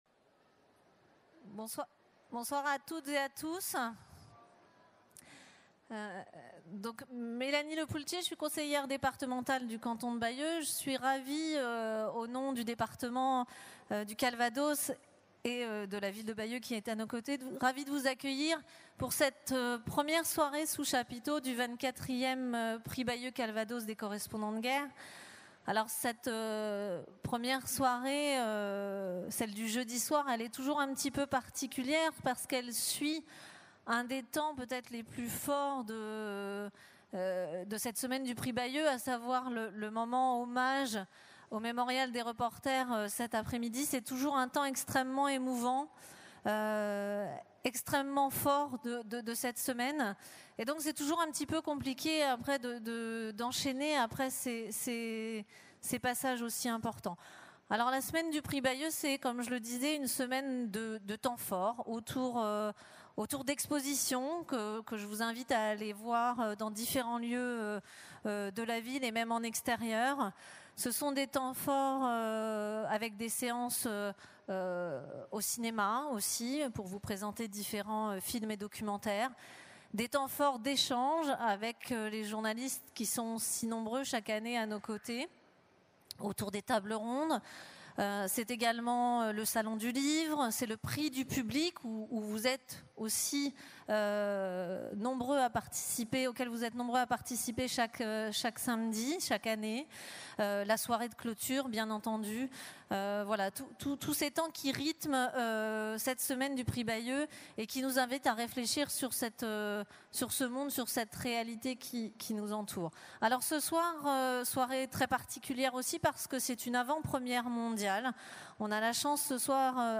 La projection fut suivie d’un échange animé